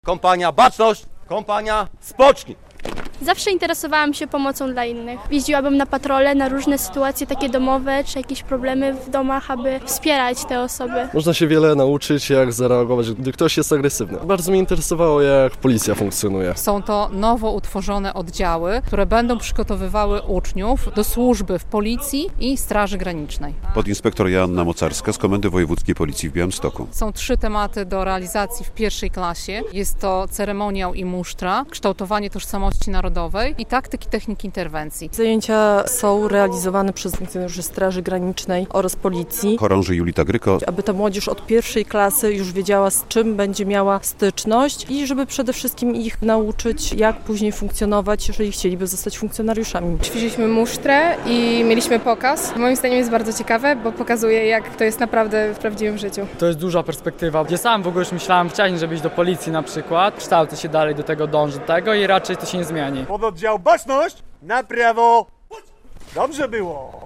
Szkolenie młodzieży z I klas mundurowych - relacja